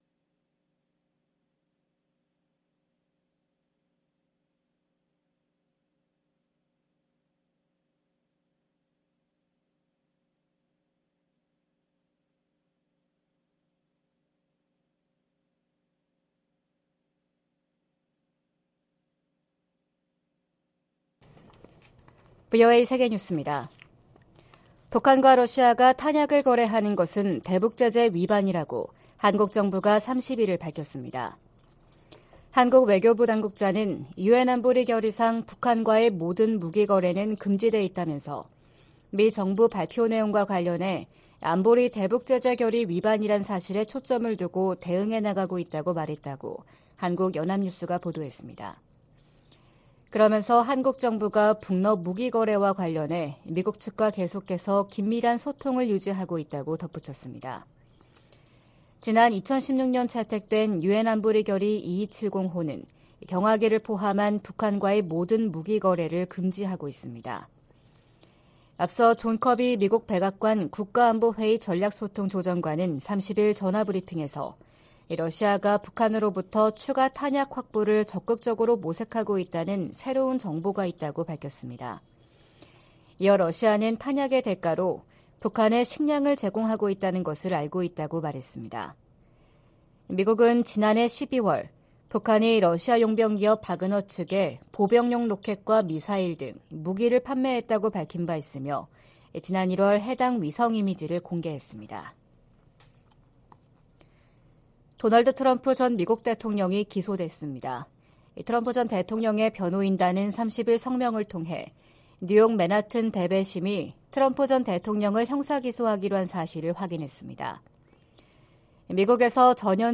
VOA 한국어 '출발 뉴스 쇼', 2023년 4월 1일 방송입니다. 미 재무부가 북한과 러시아의 무기 거래에 관여한 슬로바키아인을 제재 명단에 올렸습니다. 백악관은 러시아가 우크라이나 전쟁에서 사용할 무기를 획득하기 위해 북한과 다시 접촉하고 있다는 새로운 정보가 있다고 밝혔습니다. 미 국방부는 핵무기 한국 재배치 주장에 한반도 비핵화 정책을 계속 유지할 것이라고 밝혔습니다.